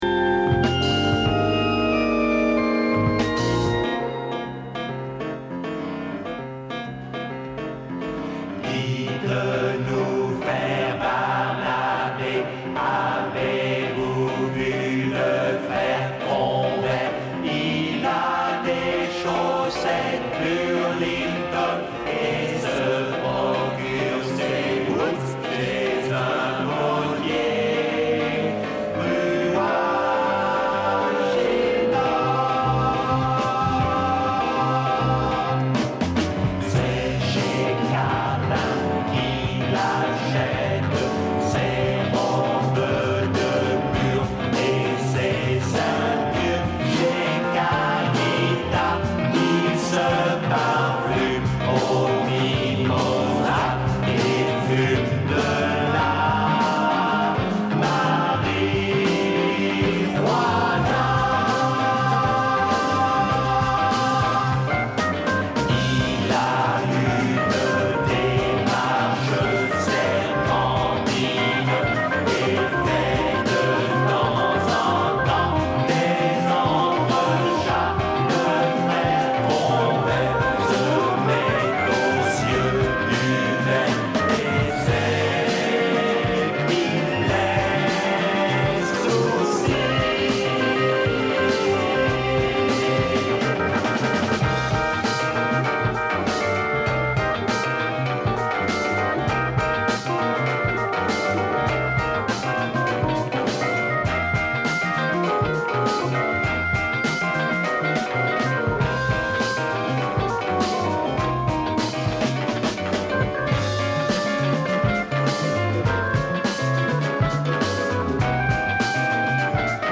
Presentazione del libro